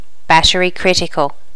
Additional sounds, some clean up but still need to do click removal on the majority.
battery critical.wav